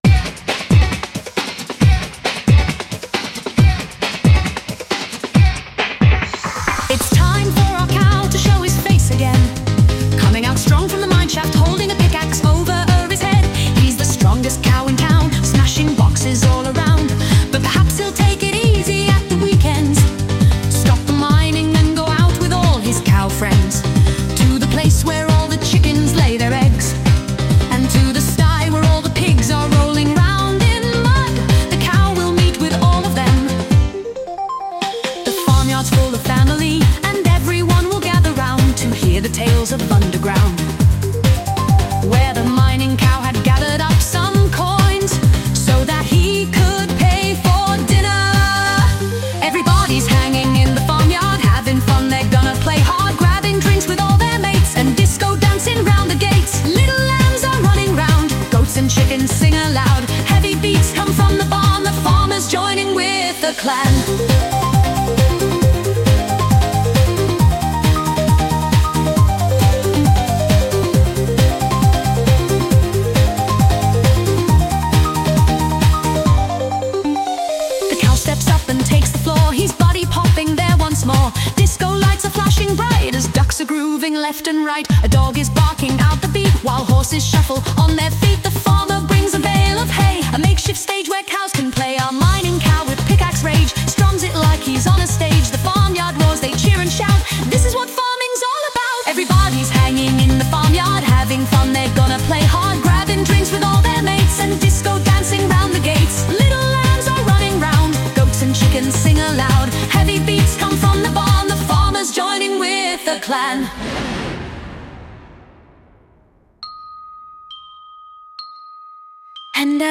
Sung by Suno
Are_We_Farmily_(Cover)_mp3.mp3